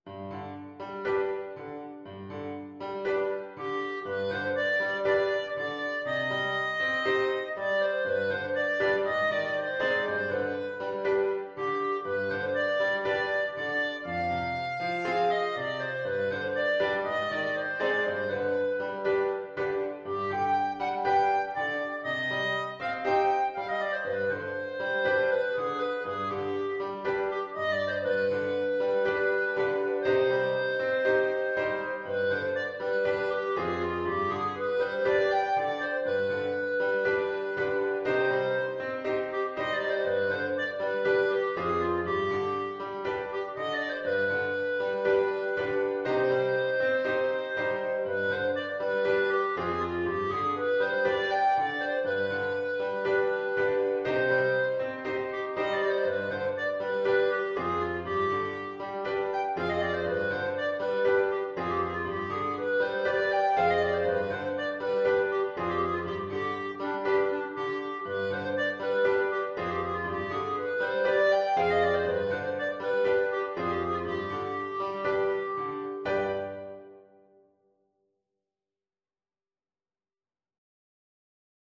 Traditional Trad. Araber Tanz (Klezmer) Clarinet version
4/4 (View more 4/4 Music)
Allegro moderato =120 (View more music marked Allegro)
C minor (Sounding Pitch) D minor (Clarinet in Bb) (View more C minor Music for Clarinet )
Clarinet  (View more Easy Clarinet Music)
Traditional (View more Traditional Clarinet Music)